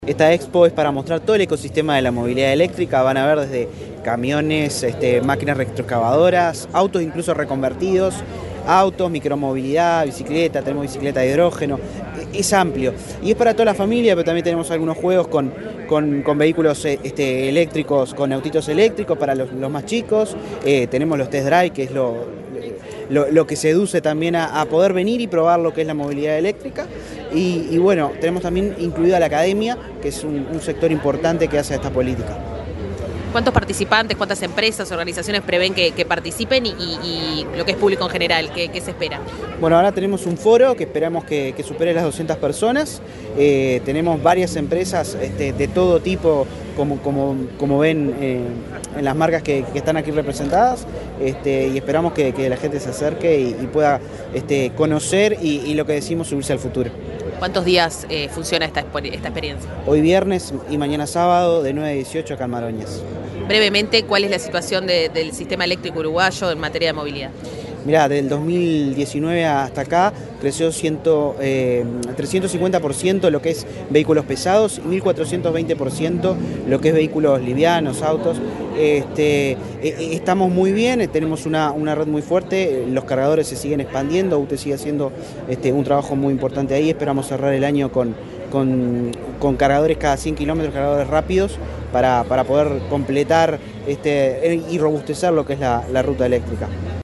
Entrevista al director nacional de Energía, Christian Nieves
Este viernes 27 en Montevideo, el director nacional de Energía, Christian Nieves, dialogó con Comunicación Presidencial, antes de participar en la